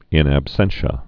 (ĭn ăb-sĕnshə, -shē-ə)